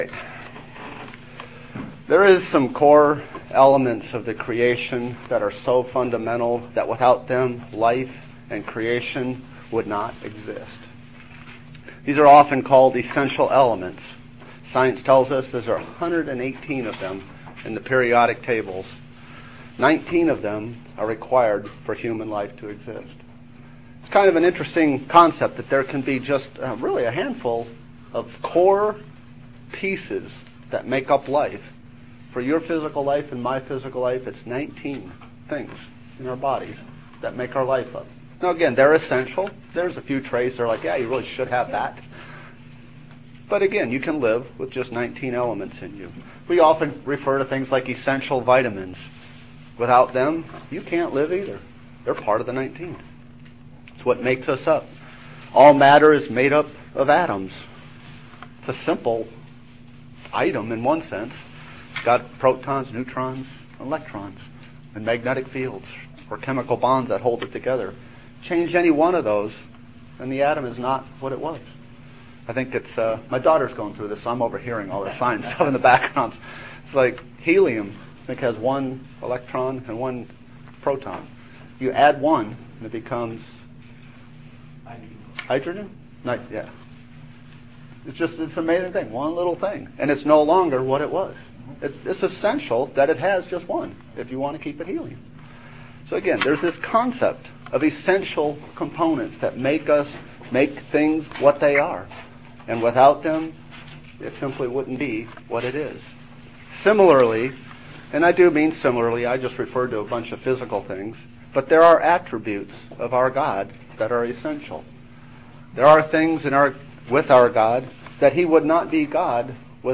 This sermon shows the absolute power of God.
Given in Bowling Green, KY